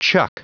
Prononciation du mot chuck en anglais (fichier audio)
Prononciation du mot : chuck